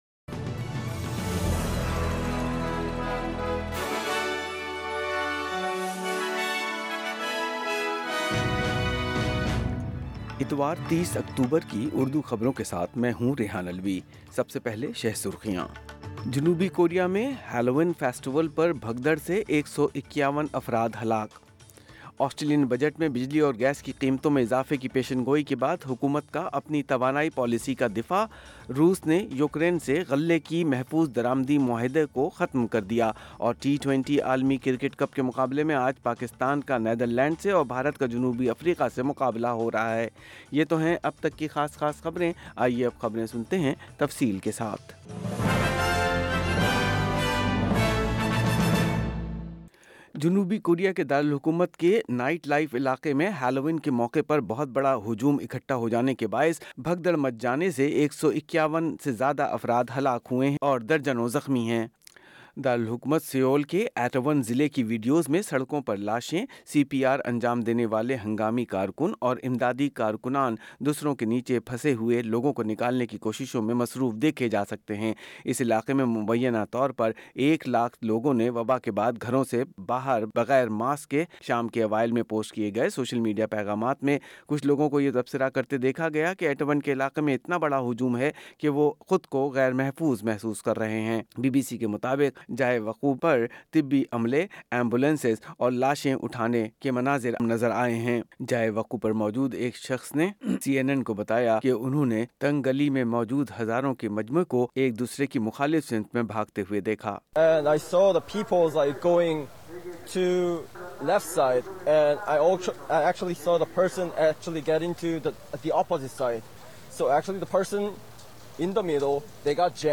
Listen full news bulletin in Urdu.